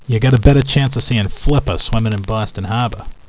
Listen to different accents
maine.wav - notice the release of the "r"s at the end of words
maine.wav